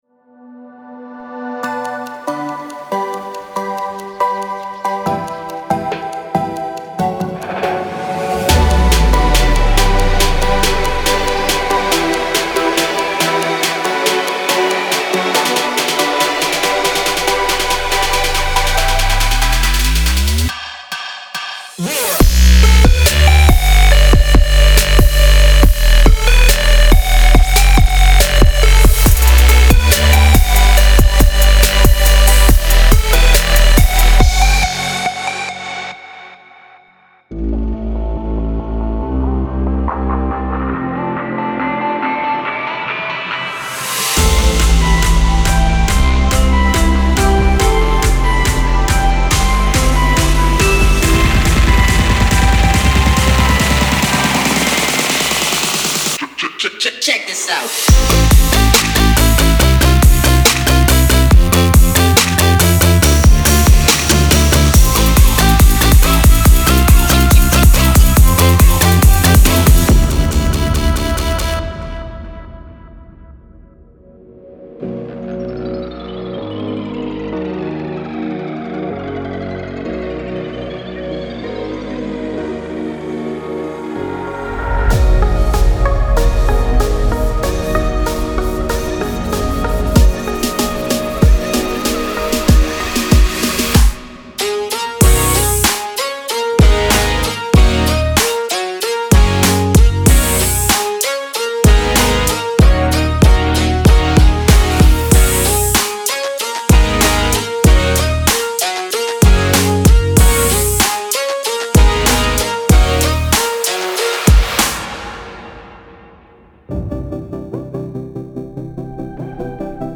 A complete collection of 85 Serum presets consisting of warm basses, uplifting keys, crisp plucks, plush pads, and unique sound effects.